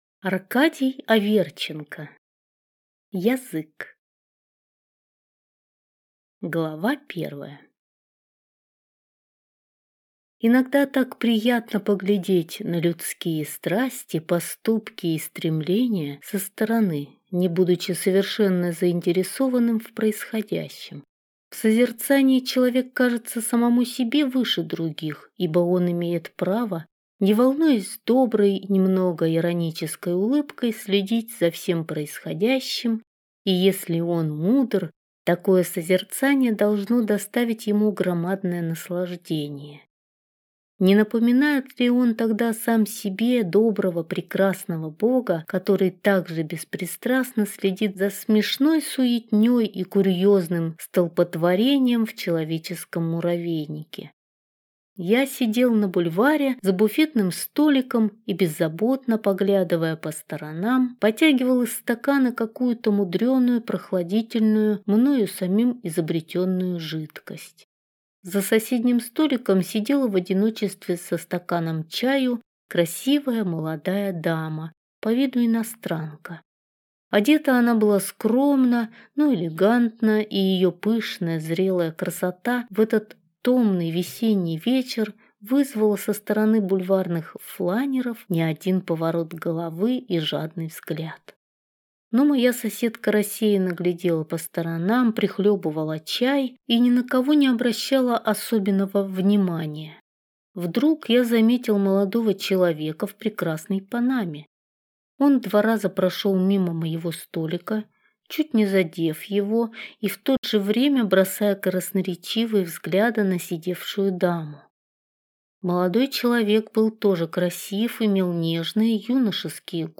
Аудиокнига Язык | Библиотека аудиокниг